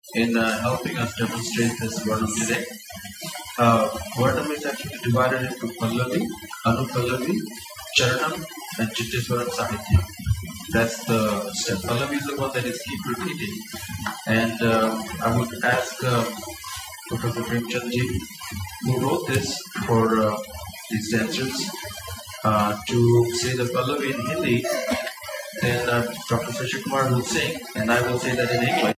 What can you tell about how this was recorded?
Here's the recording put through the Audacity noise reduction plugin. You'll hear the artifacts. audacity_noise_reduction.mp3